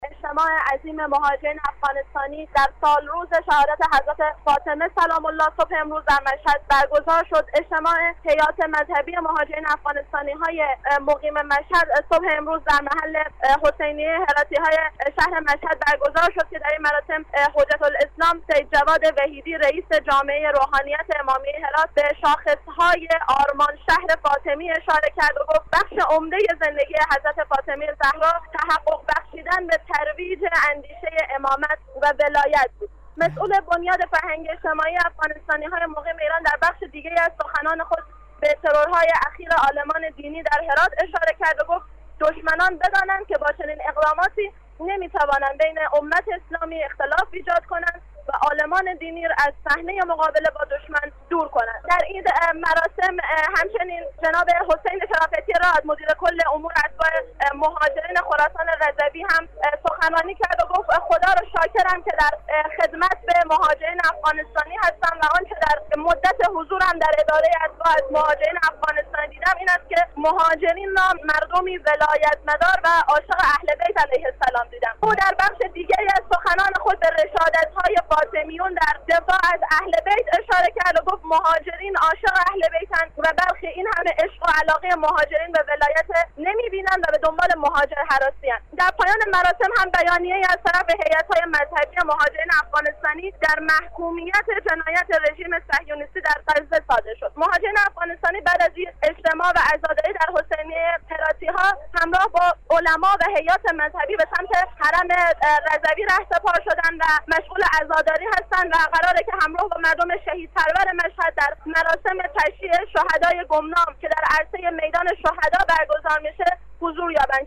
اجتماع عظیم مهاجران افغانستانی در سالروز شهادت حضرت زهرا (س) صبح امروز در مشهد برگزار شد.